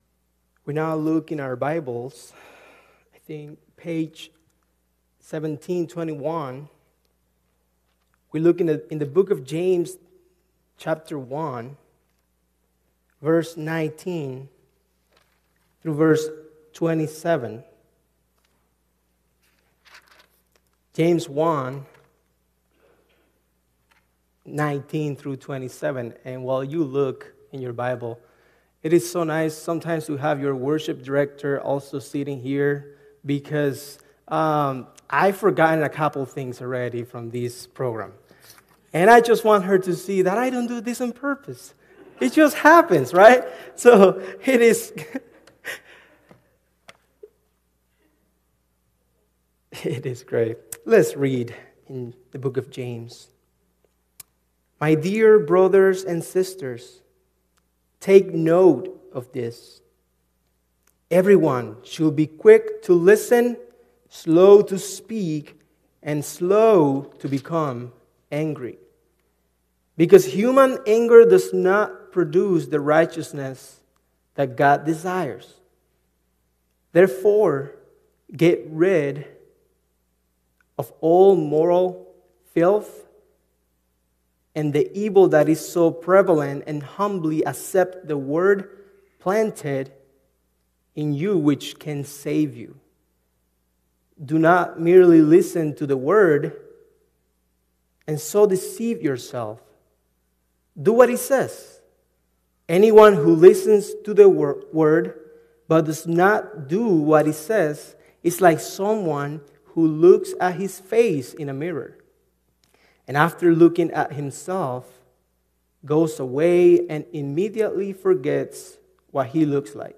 Sermon Recordings | Faith Community Christian Reformed Church
“A Faith You Can See” February 8 2026 P.M. Service